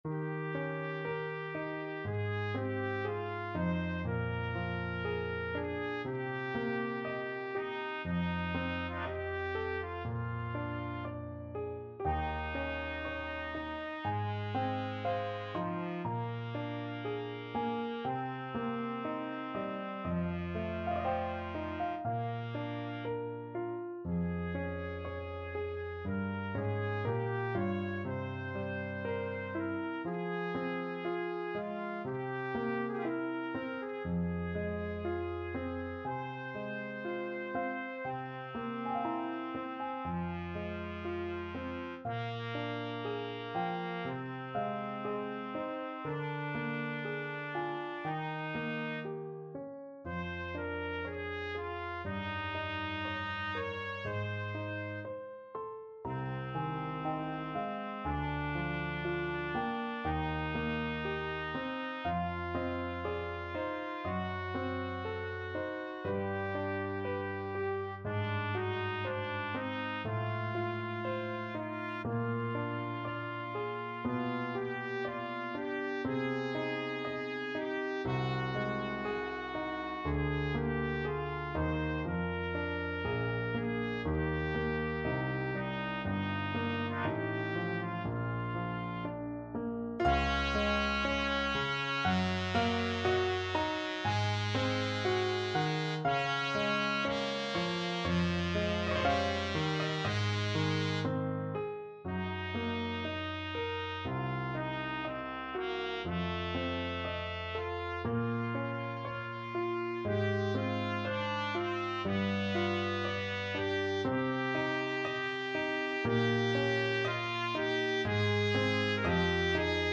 Classical Chopin, Frédéric Cello Sonata, Op.65 Third Movement, Largo Trumpet version
Trumpet
Eb major (Sounding Pitch) F major (Trumpet in Bb) (View more Eb major Music for Trumpet )
3/2 (View more 3/2 Music)
~ = 60 Largo
Classical (View more Classical Trumpet Music)
chopin_cello_sonata_3rd_mvt_TPT.mp3